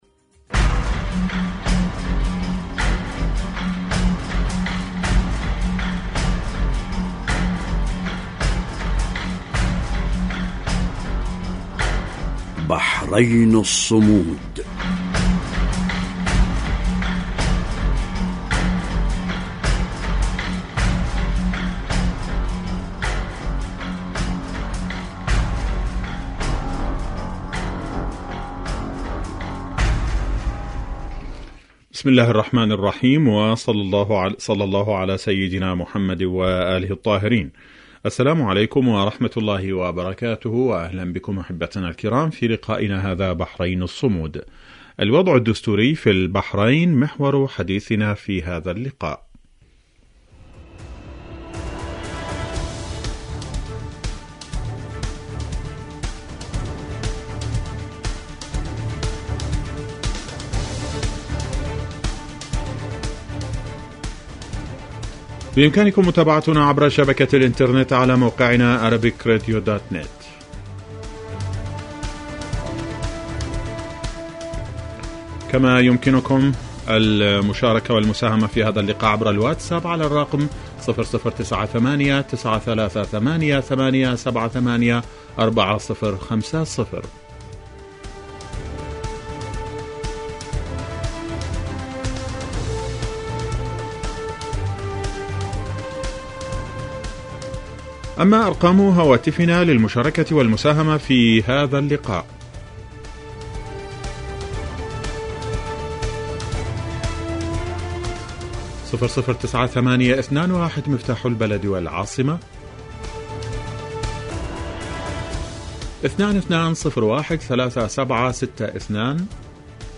إذاعة طهران-بحرين الصمود